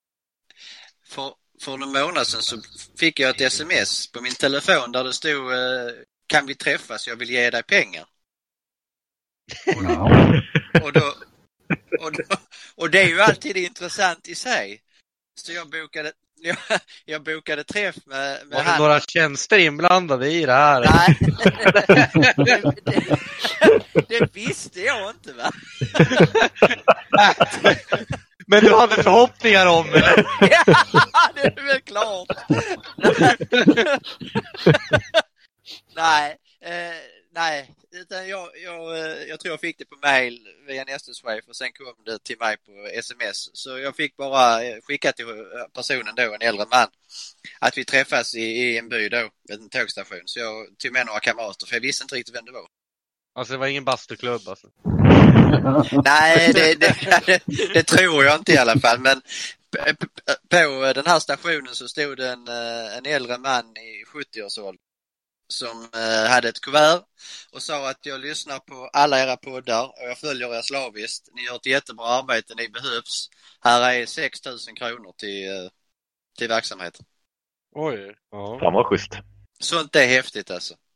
Här följer lite extramaterial från senaste inspelningen av Mer än ord, som inte fick plats i avsnittet då det egentligen bara var sidosnack under en av musikpauserna.